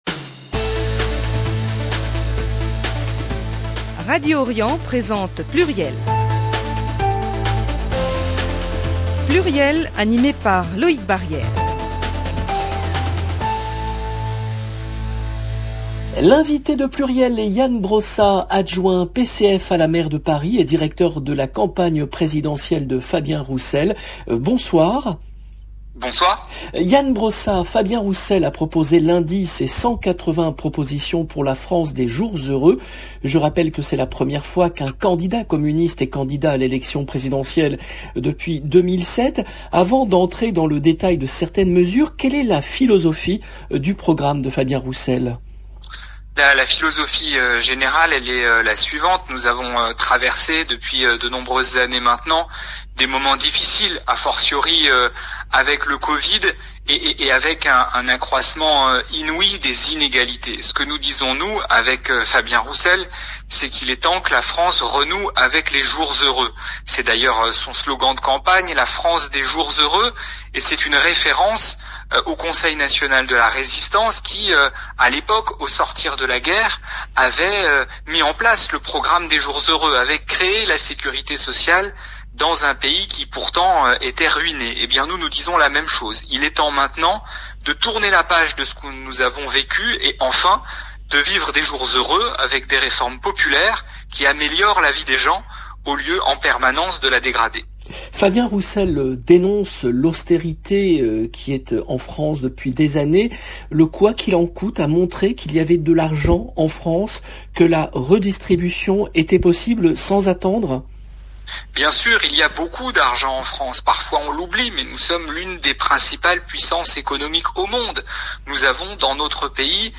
L’invité de PLURIEL est Ian Brossat , adjoint PCF à la maire de Paris et directeur de la campagne présidentielle de Fabien Roussel